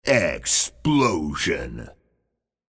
Explosion audio from the Halo: Reach Clicktacular YouTube Masthead.
HR_-_Marketing_-_Clicktacular_-_Explosion.mp3